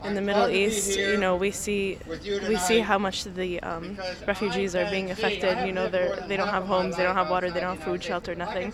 PALESTINIAN STUDENT FROM SAUDI ARABIA AT A PRO-REFUGEE PROTEST IN BOSTON SPEAKS ABOUT THE REFUGEES SHE SEES BACK HOME.
A-PALESTINIAN-STUDENT-FROM-SAUDI-ARABIA-SAYS-IN-THE-MIDDLE-EAST-THEY-SEE-HOW-MUCH-THE-REFUGEES-ARE-SUFFERING-.mp3